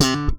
ALEM FUNK D4.wav